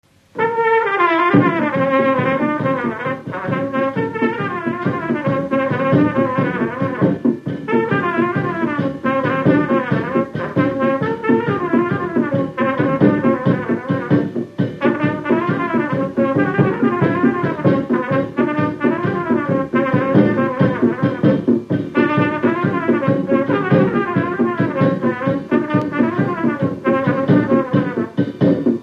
Dallampélda: Hangszeres felvétel
Moldva és Bukovina - Bukovina - Andrásfalva
Stílus: 7. Régies kisambitusú dallamok
Kadencia: b3 (1) b3 1